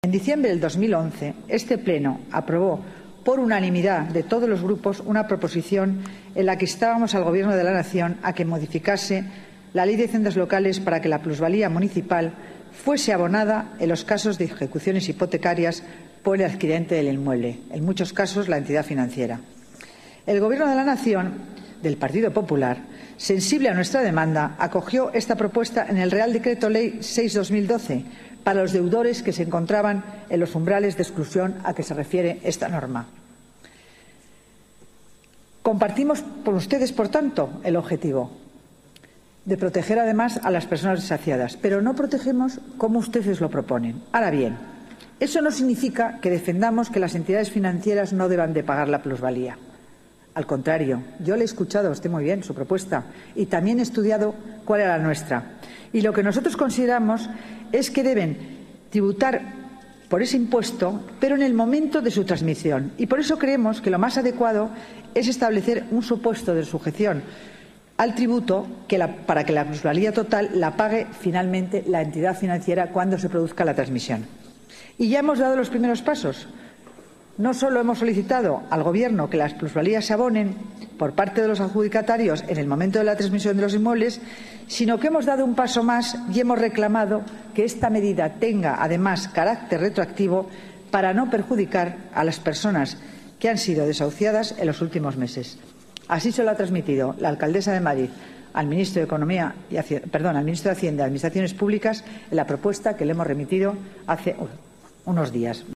Nueva ventana:Declaraciones de la delegada de Economía, Hacienda y Administración Pública, Concepción Dancausa